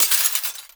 GLASS_Window_Break_03_mono.wav